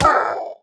minecraft-assets / assets / minecraft / sounds / mob / parrot / death2.ogg